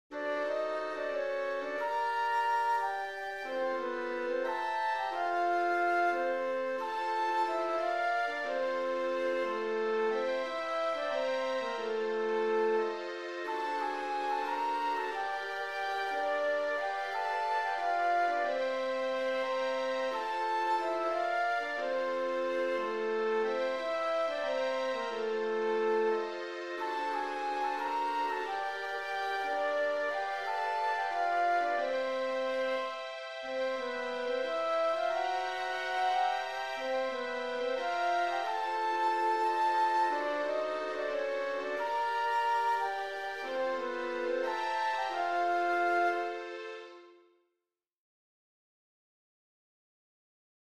Mota: Euskal Musika